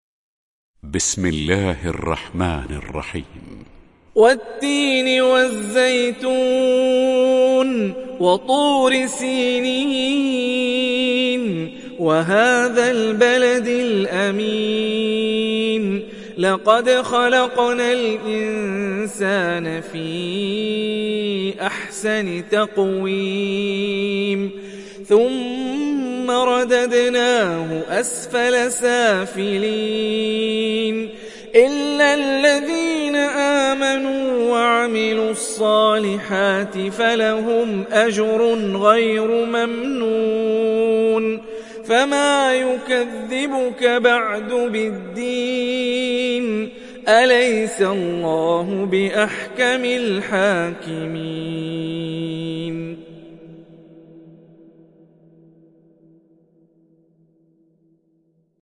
تحميل سورة التين mp3 بصوت هاني الرفاعي برواية حفص عن عاصم, تحميل استماع القرآن الكريم على الجوال mp3 كاملا بروابط مباشرة وسريعة